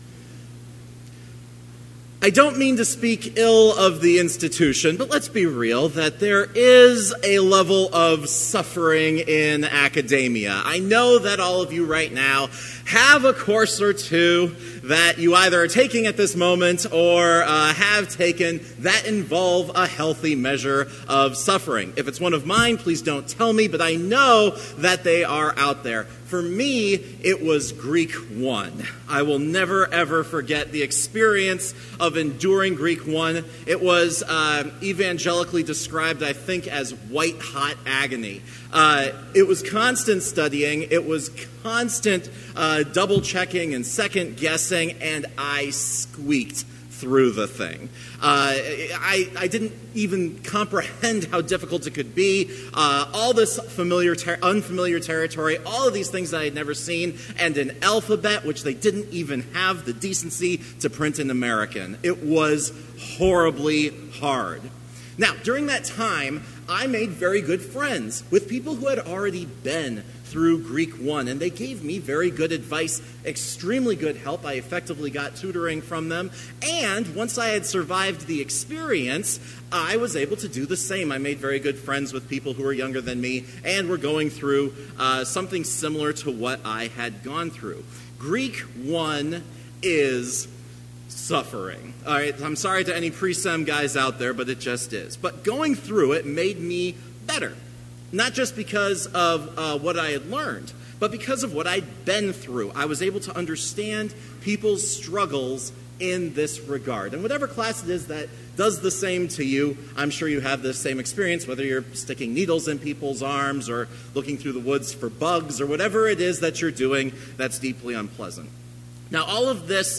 Complete service audio for Chapel - October 23, 2018